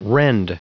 Prononciation du mot rend en anglais (fichier audio)
Prononciation du mot : rend